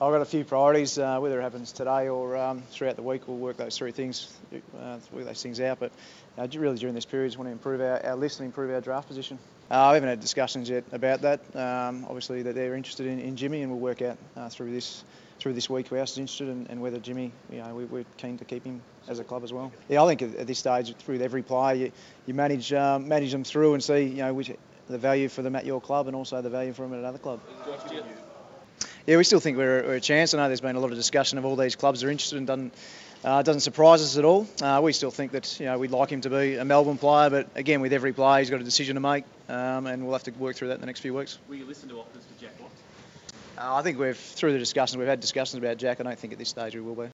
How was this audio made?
addressed the media on the first day of the AFL Trade Period.